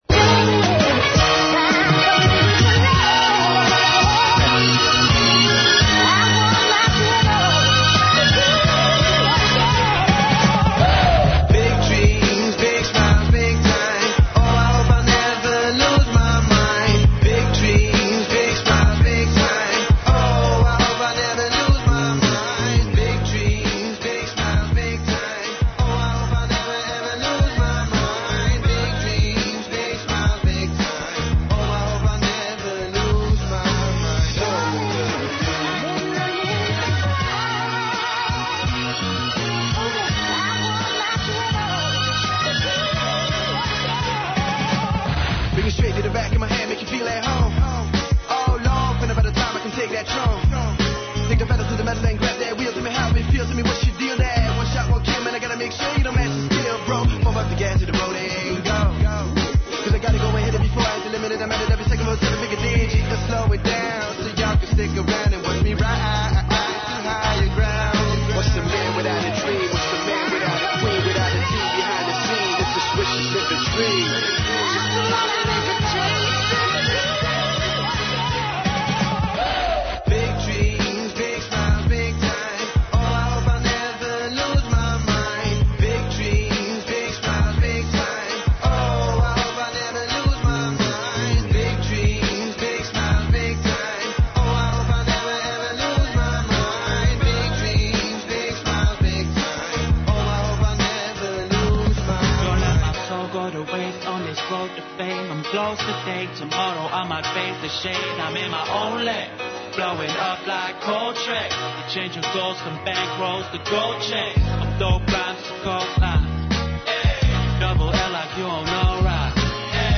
Уживо, са највеће европске музичке платформе Eurosonik Noorderslag, који се одржава у Гронингену (Холандија) представљамо ексклузивно најзначајније европске извођаче.